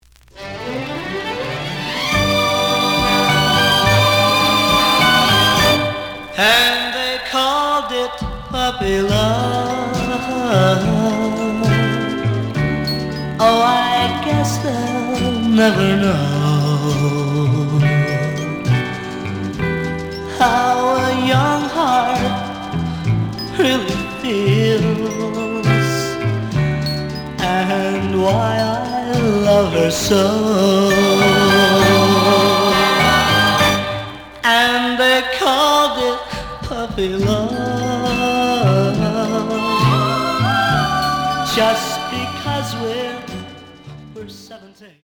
The audio sample is recorded from the actual item.
●Genre: Rock / Pop
Some click noise on first half of A side due to a bubble.